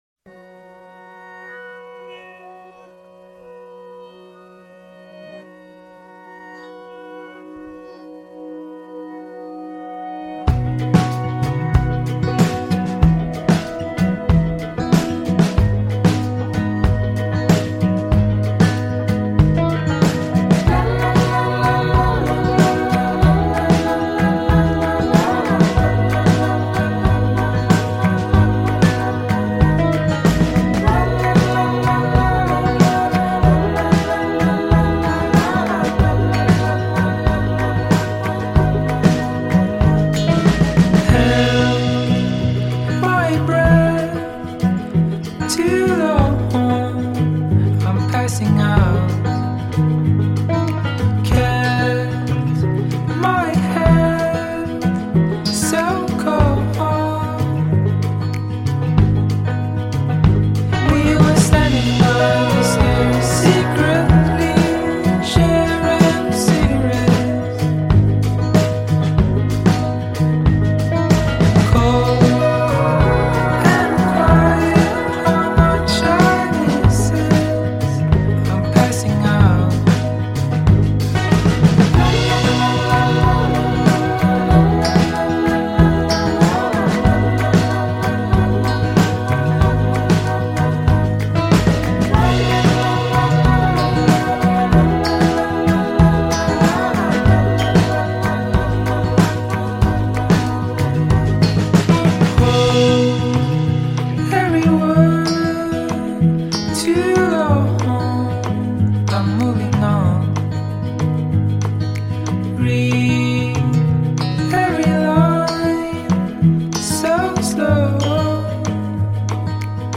Oslo Norway based singer-songwriter
inspiring indie folk flavored single